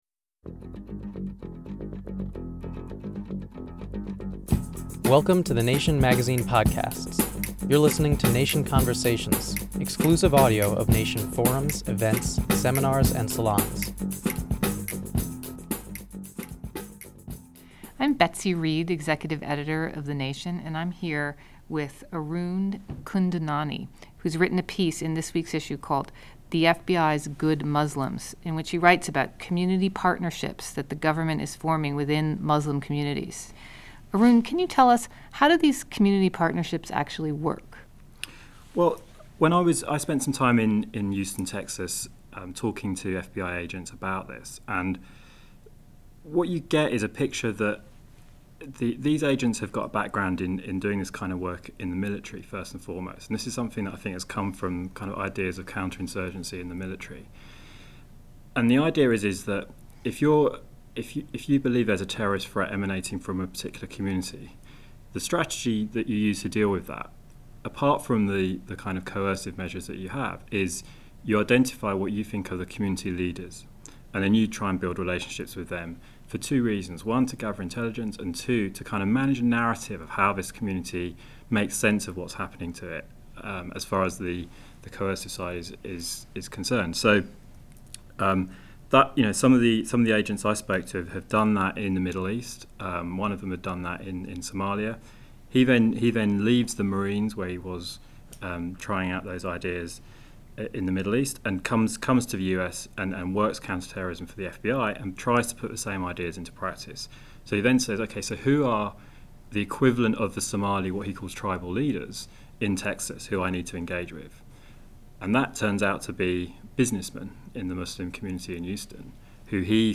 Subscribe to Nation Conversations on iTunes for exclusive audio of Nation forums, events, seminars, and salons.